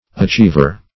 Achiever \A*chiev"er\, n.